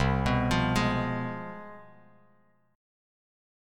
CM7sus4#5 chord